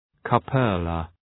Προφορά
{‘kju:pələ}